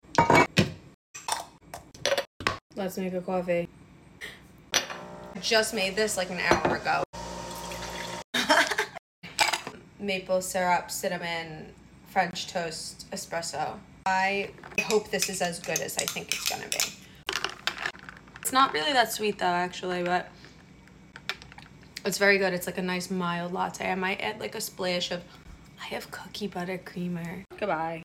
making French toast latte with almond milk at home using Nespresso Verturo machine ☕